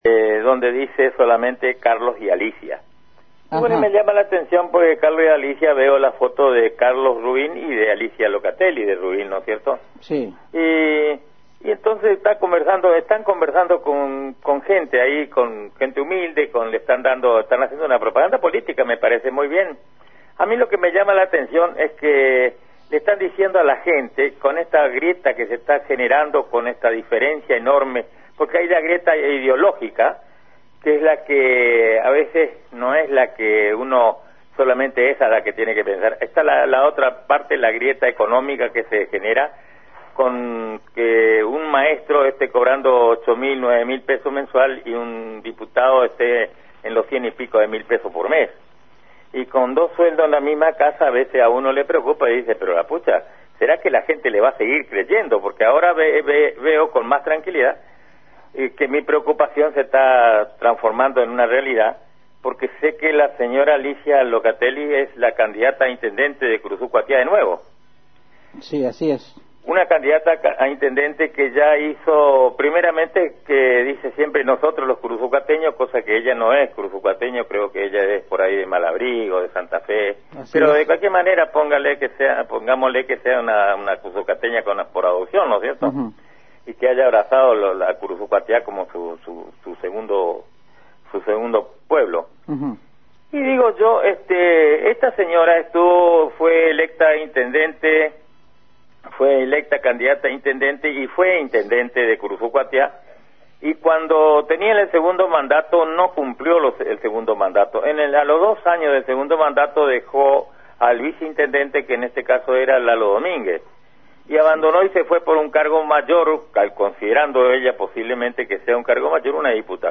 En resumidas cuentas, el ciudadano tomó la palabra y valientemente dijo ante la inmensa audiencia de la AM 970 Radio Guarani que "esta señora fue electa candidata a Intendente por el pueblo que con mucha honestidad la votó, y al ser reelecta a los dos años se mandó a mudar por un cargo mejor".